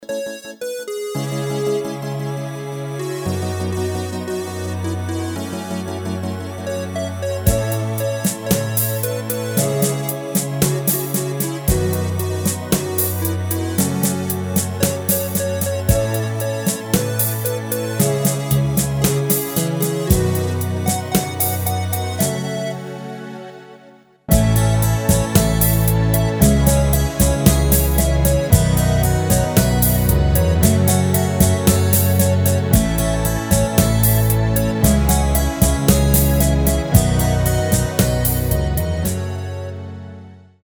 Rubrika: Pop, rock, beat
Nejnovější MP3 podklady